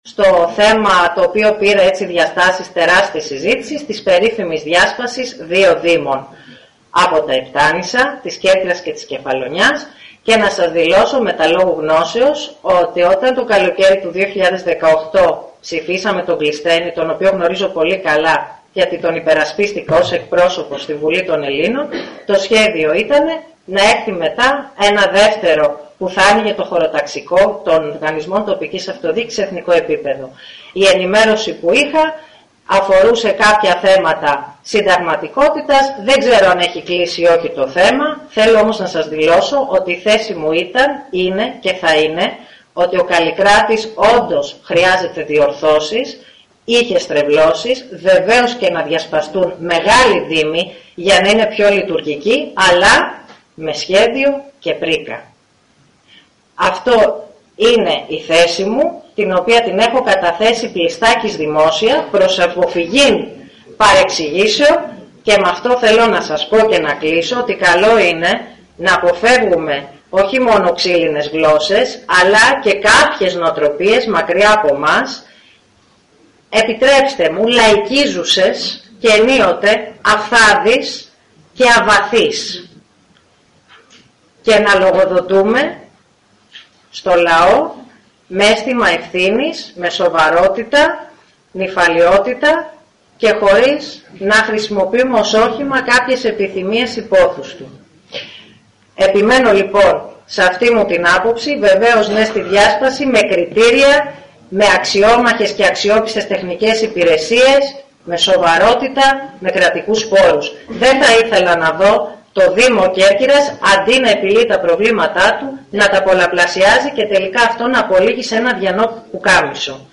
Η υπόθεση της διάσπασης των νησιωτικών δήμων και ειδικότερα του δήμου της Κέρκυρας, έχει προκαλέσει κόντρα μεταξύ των δύο βουλευτών του ΣΥΡΙΖΑ, η οποία συνεχίστηκε και χθες στην ειδική συνεδρίαση του περιφερειακού συμβουλίου στην οποία παρέστησαν ο Κώστας Παυλίδης και η Φωτεινή Βάκη.